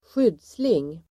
Uttal: [²sj'yd:sling]